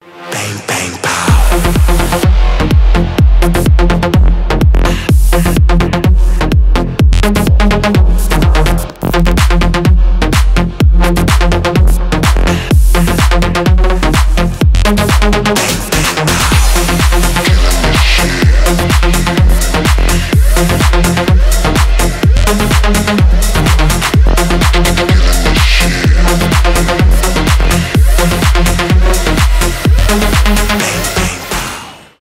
техно
клубные , electro house